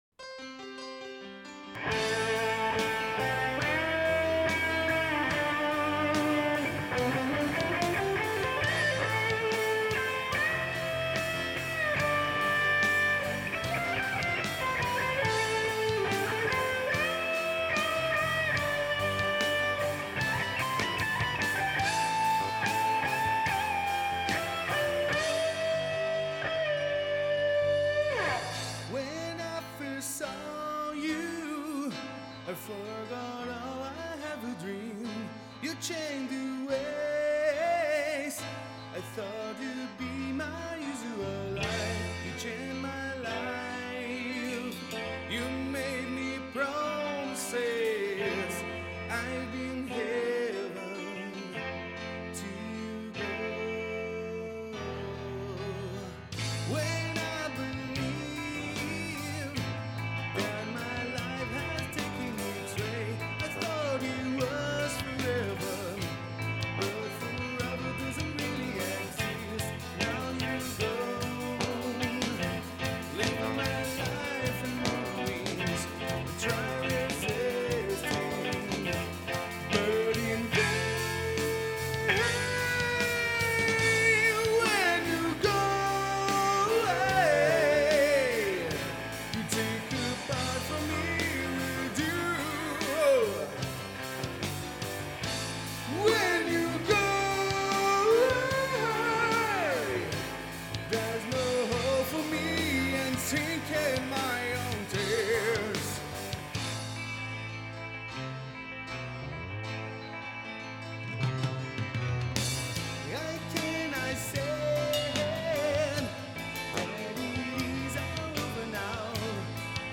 • Bass
• Keyboards
We recorded these two songs in a rented 24 track studio.
I also think that the guitar and piano clash a little bit.